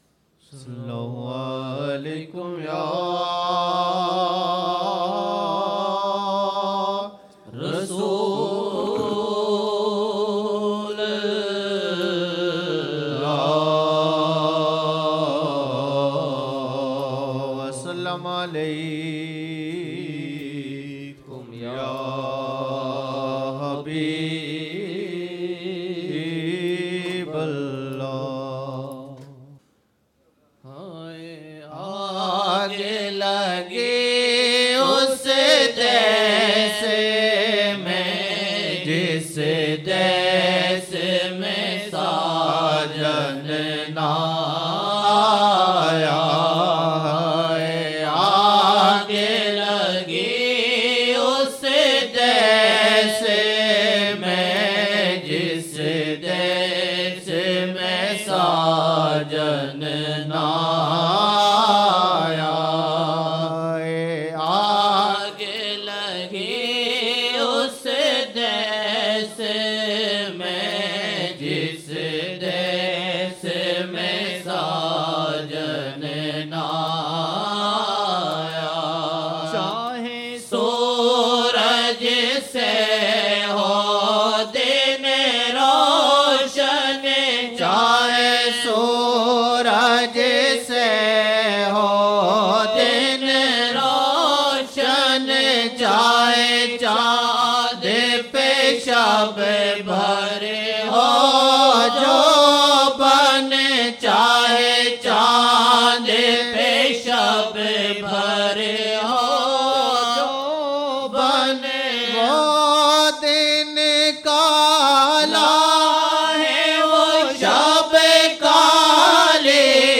Naat(Haaye aag lagi us des mein, jis des mein sajan na aaya) 2007-01-02 02 Jan 2007 Old Naat Shareef